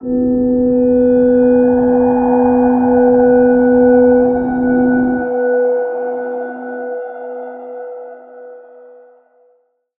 G_Crystal-B4-f.wav